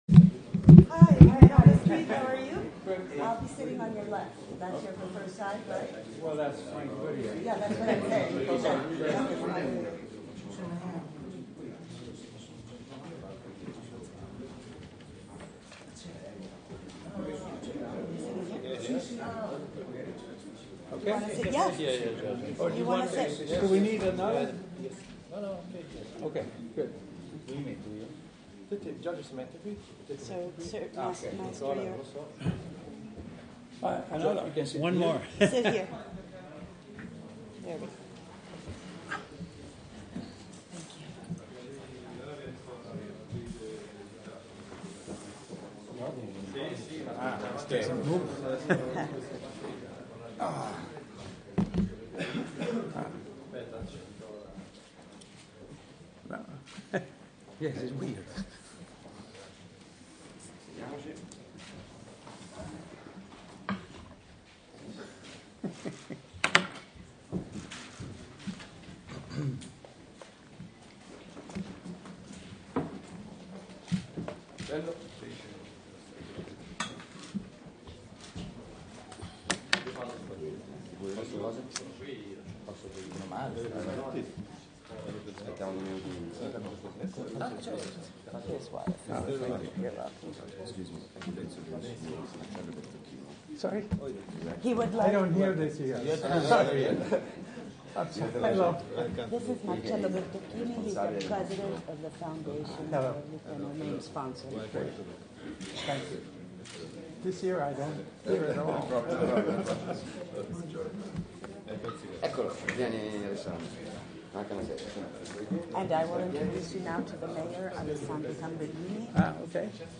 Conferenza stampa con George A. Romero al Lucca Film Festival
Nella mattinata di ieri, in occasione della nuova edizione del Lucca Film Festival, si è tenuta la conferenza stampa del regista George A. Romero (La notte dei morti viventi, Wampyr, Creepshow, La terra dei morti viventi).
Nell’arco di un’ora il regista si è dimostrato di una lucidità e di un’ironia disarmanti.
CONFERENZA-STAMPA-ROMERO.lite_.mp3